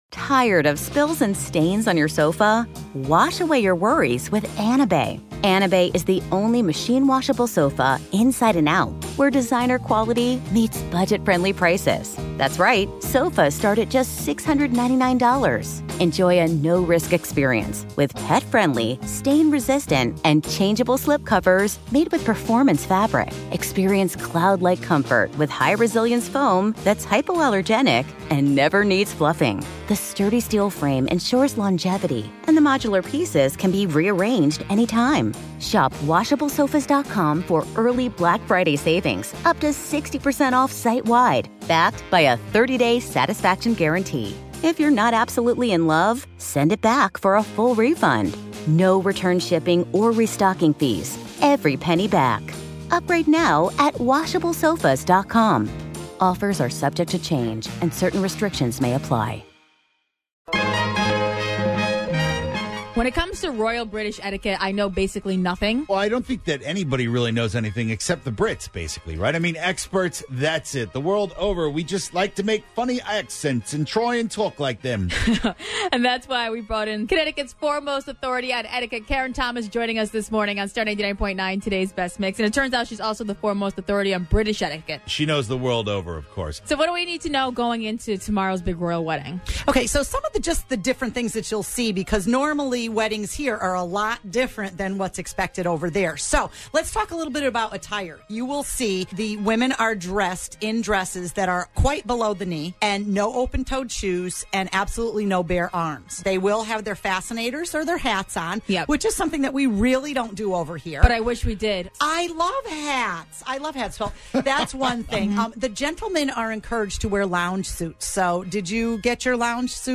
came into the studio to give us the Do's and Dont's of attending a Royal Wedding, or any royal gathering for that matter!